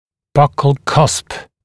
[ˈbʌkl kʌsp][ˈбакл касп]щечный бугор (зуба)